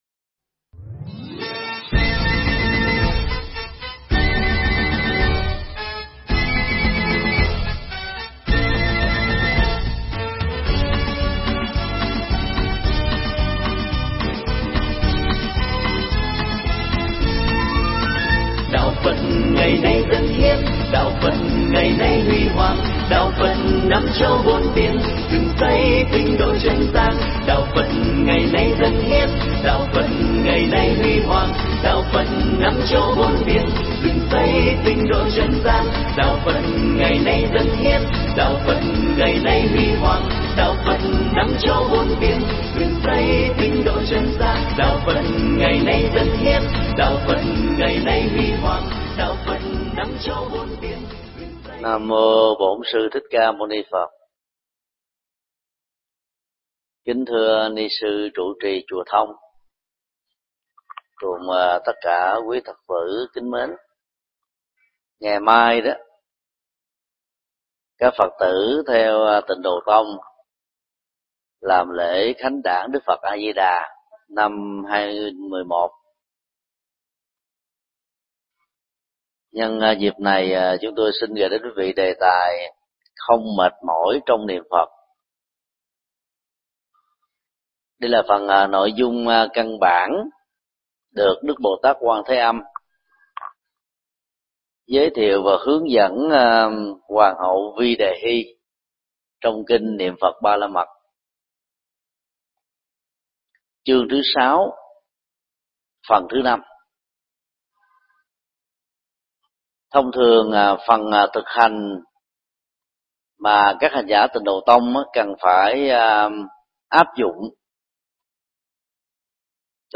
Tải Bài giảng mp3 Kinh Niệm Phật Ba La Mật 17: Không mệt mỏi được thầy Thích Nhật Từ giảng tại Chùa Thông, Hà Nội, ngày 10 tháng 12 năm 2011